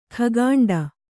♪ khagāṇḍa